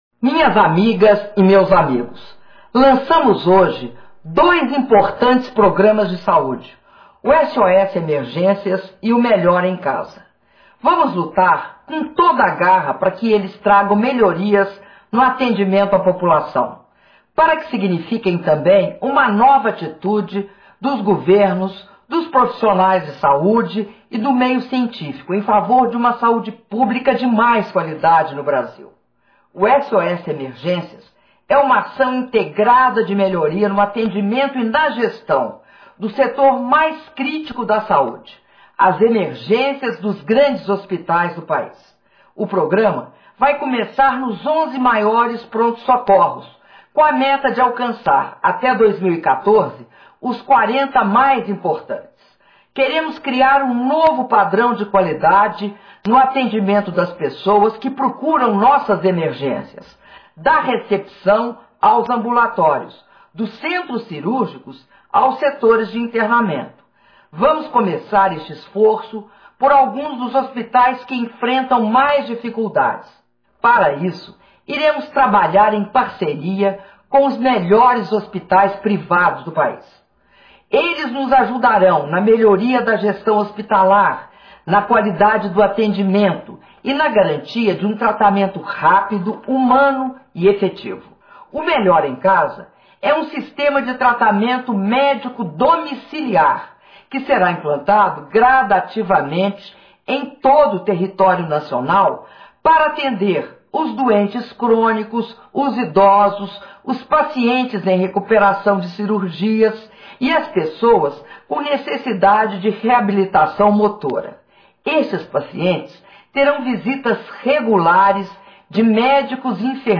Pronunciamento da Presidenta da República, Dilma Rousseff, sobre os Programas Melhor em Casa e SOS Emergências - Brasília/DF